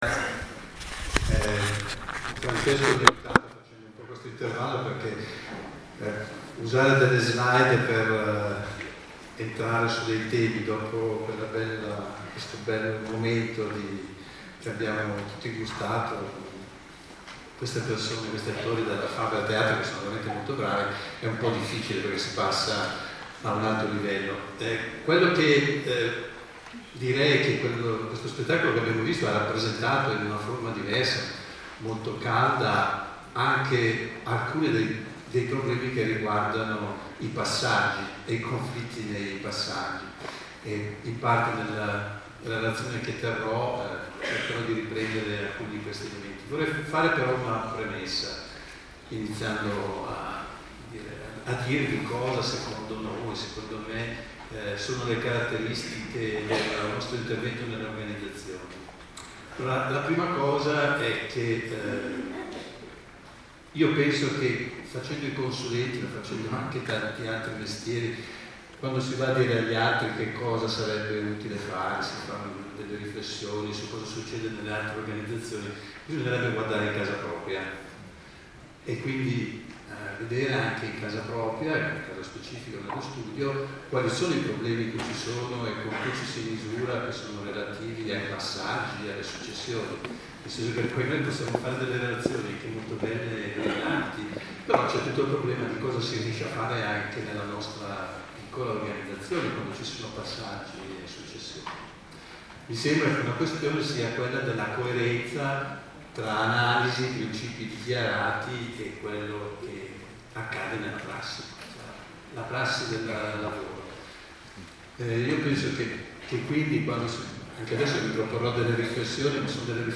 Giornate di Studio 2018 – Presentazione, programma e scheda di iscrizione